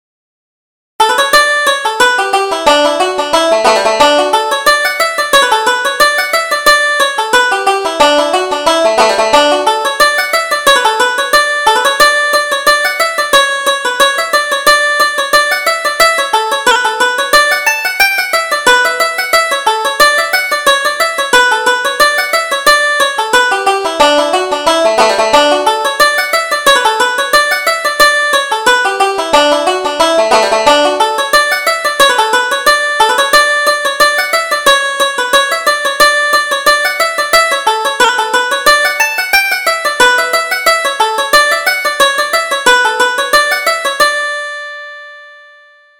Reel: The Lady behind the Boat